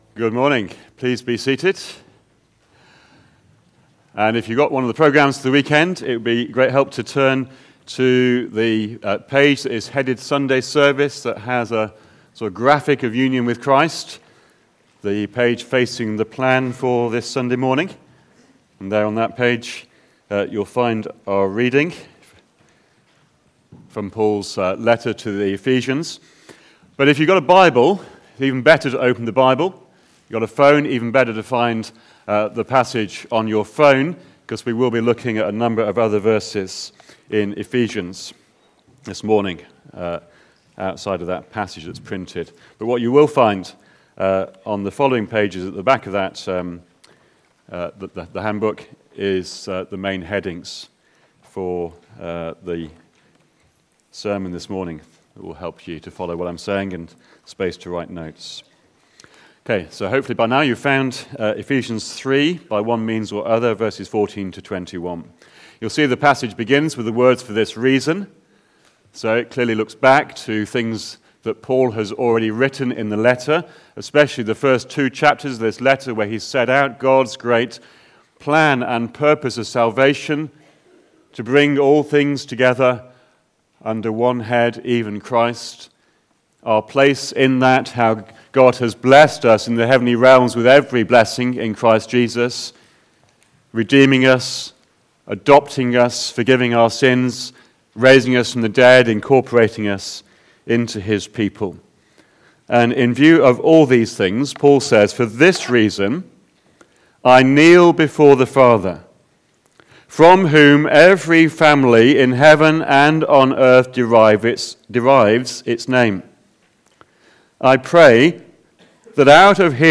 Back to Sermons Union with Christ Part 3